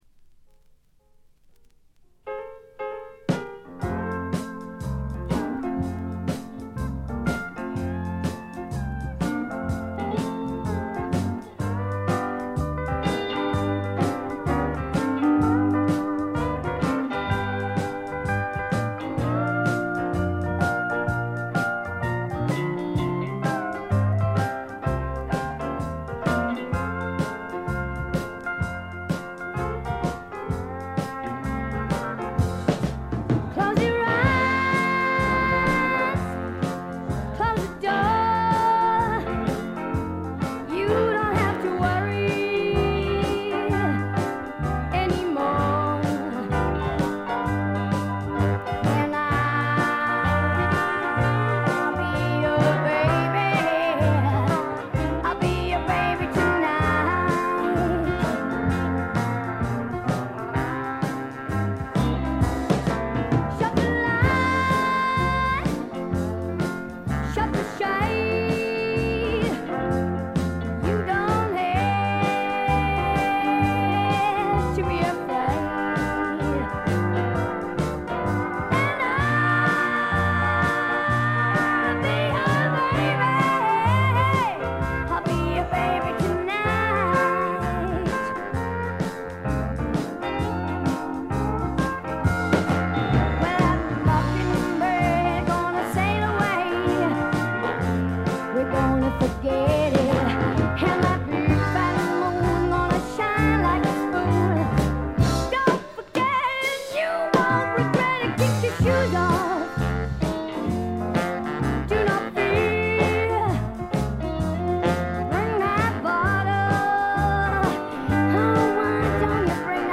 部分試聴ですが軽微なチリプチと散発的なプツ音が少し出る程度。
試聴曲は現品からの取り込み音源です。